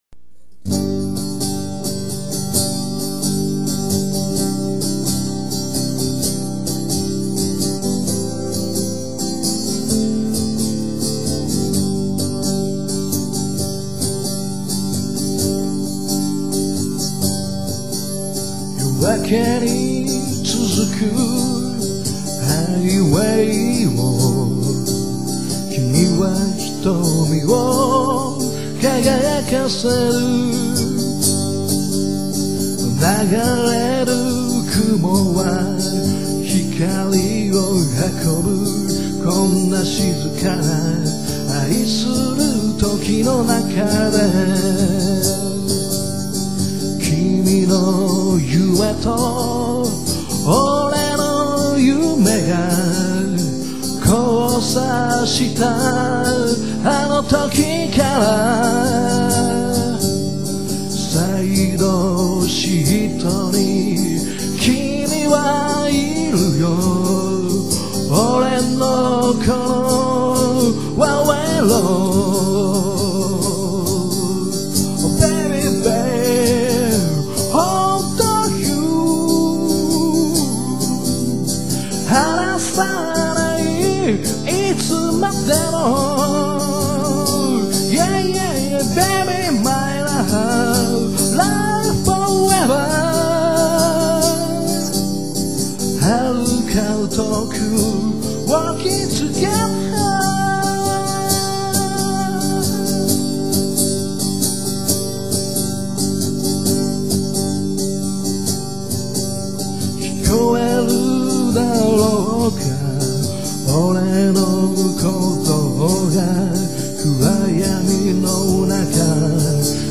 E/Guitar + Vocal